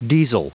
Prononciation du mot diesel en anglais (fichier audio)
Prononciation du mot : diesel